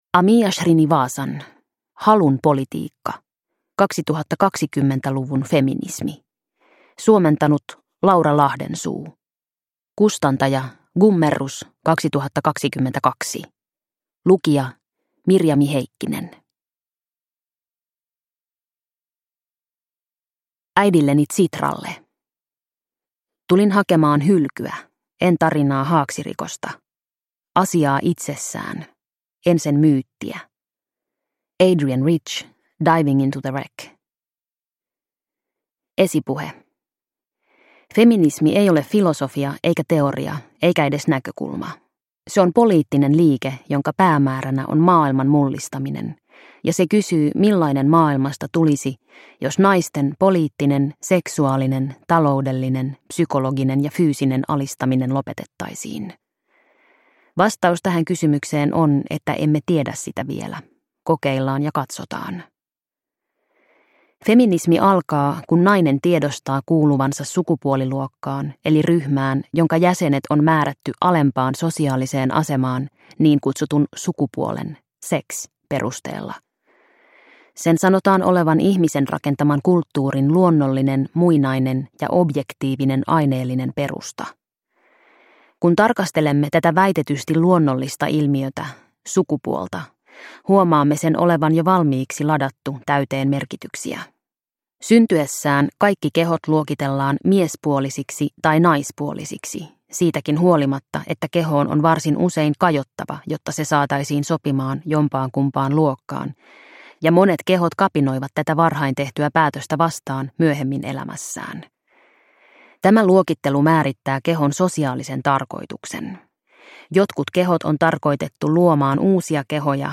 Halun politiikka – Ljudbok – Laddas ner